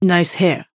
Yamato voice line - Nice hair.